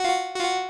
フリー効果音：警告
システムエラーで鳴り響く音です！
warning.mp3